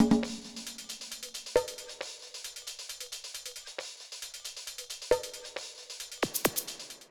KIN Beat - Full Perc 1.wav